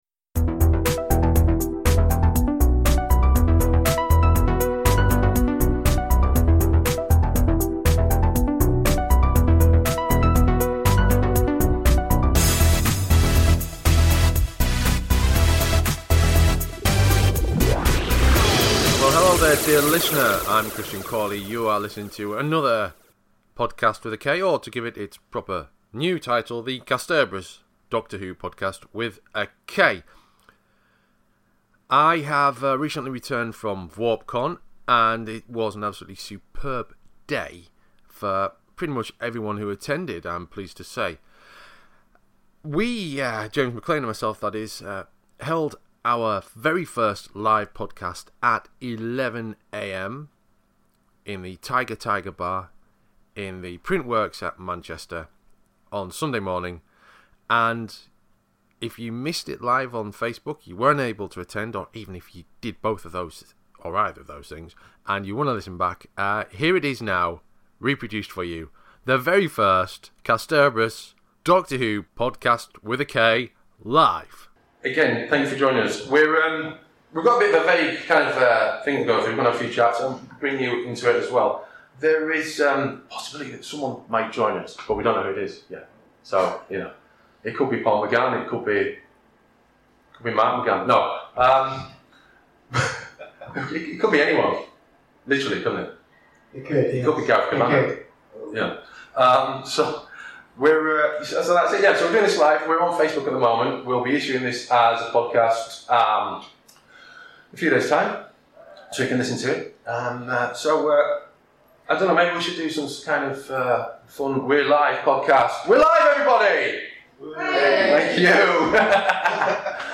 We're pleased to present to you our very first Doctor Who podcast with a live audience!